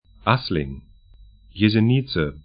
Pronunciation
Assling 'aslɪŋ Jesenice jeze'ni:tsə sl Stadt / town 46°27'N, 14°04'E